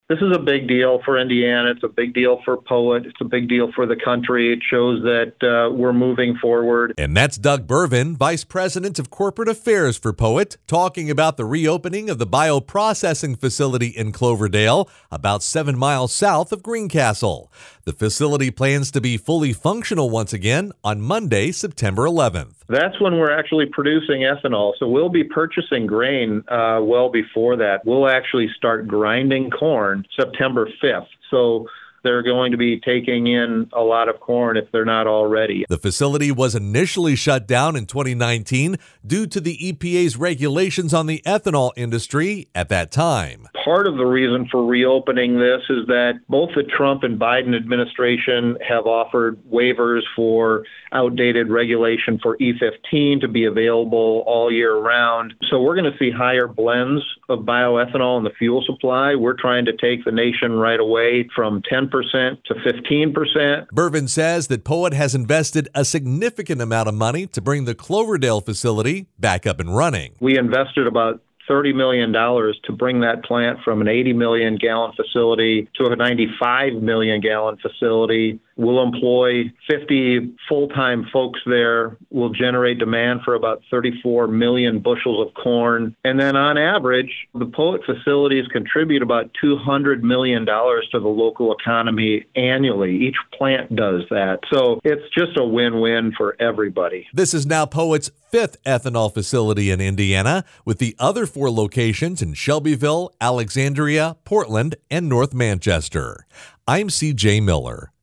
radio news report